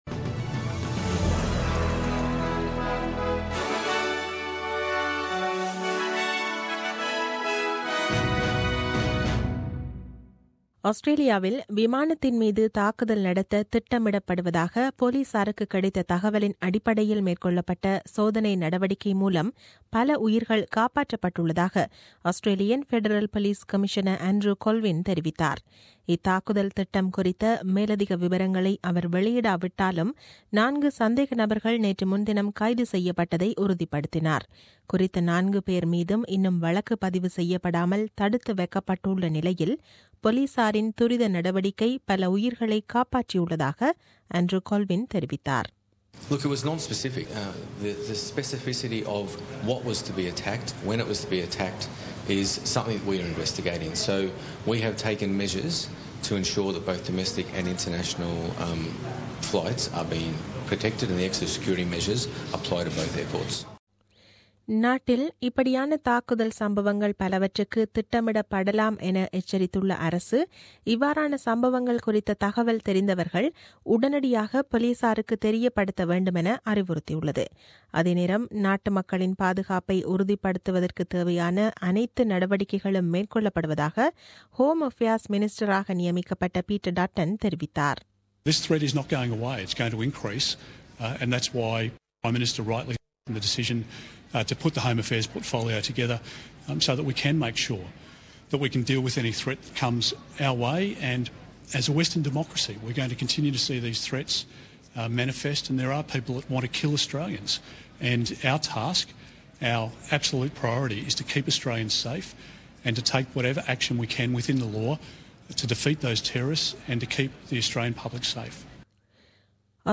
The news bulletin aired on 31 July 2017 at 8pm.